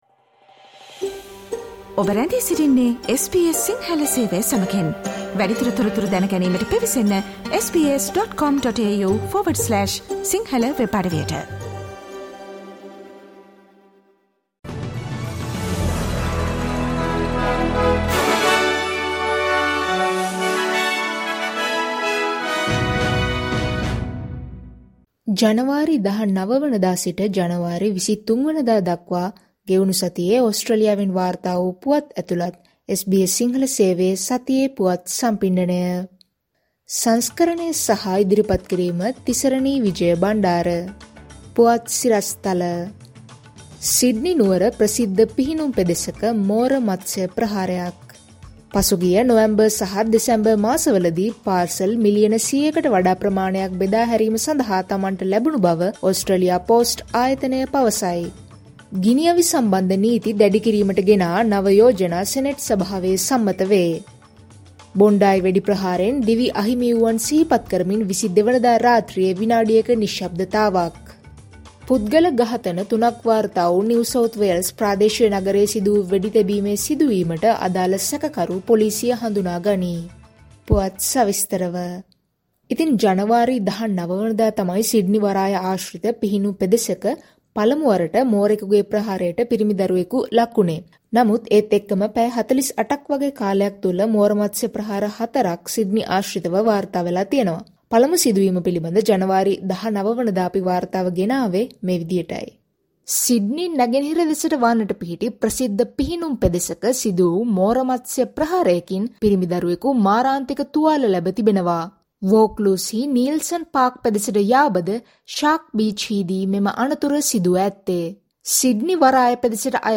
ජනවාරි 19වන දා සිට ජනවාරි 23වන දා දක්වා වන මේ සතියේ ඕස්ට්‍රේලියාවෙන් වාර්තා වන පුවත් ඇතුළත් SBS සිංහල සේවයේ සතියේ පුවත් ප්‍රකාශයට සවන් දෙන්න.